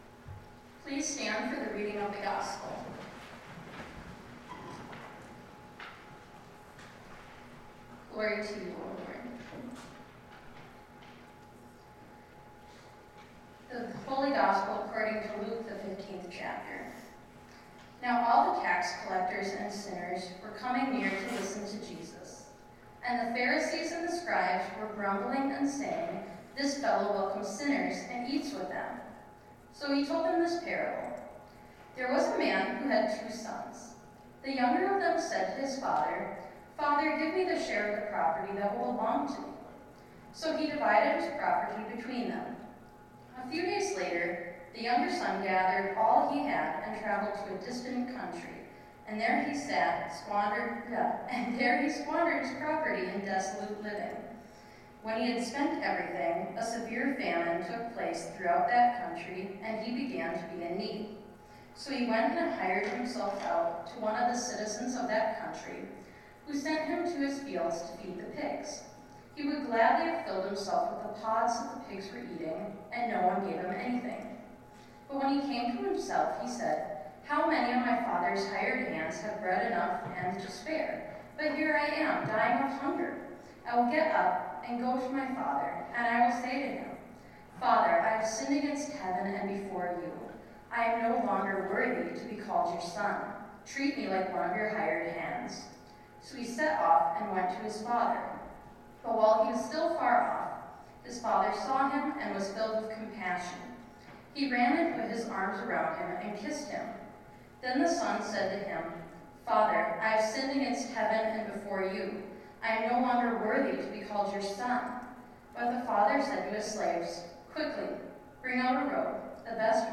Sermons by United Lutheran Church